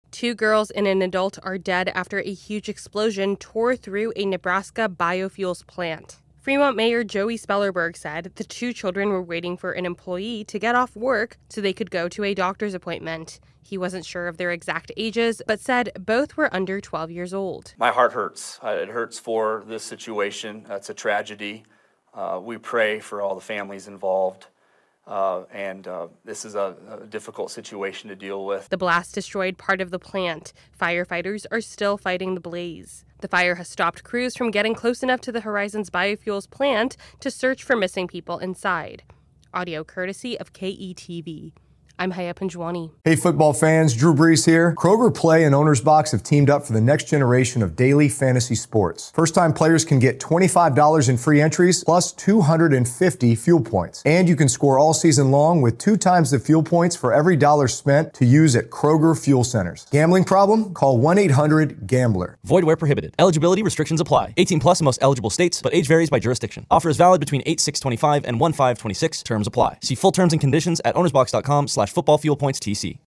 AP correspondent
reports